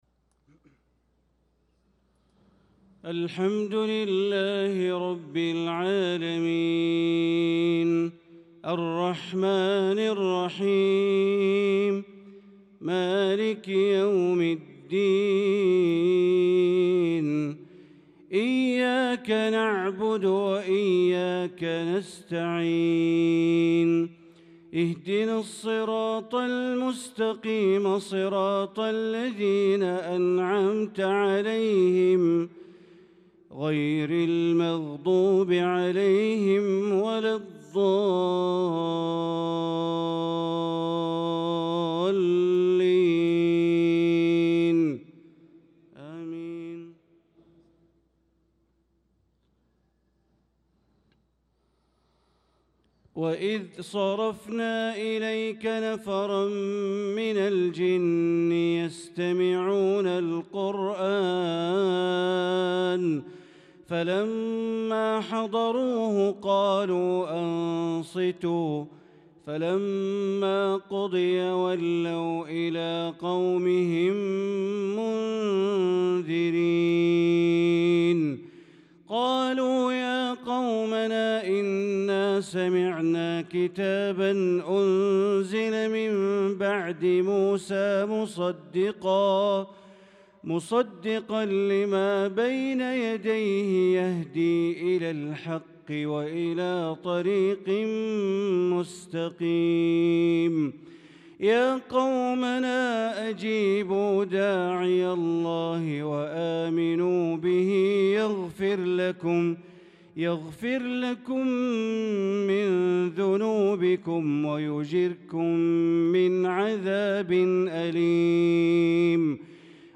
صلاة العشاء للقارئ بندر بليلة 17 شوال 1445 هـ
تِلَاوَات الْحَرَمَيْن .